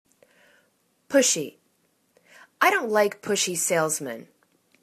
push.y     /'poshi/    adj
pushy.mp3